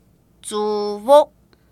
臺灣客語拼音學習網-進階學習課程-海陸腔-第八課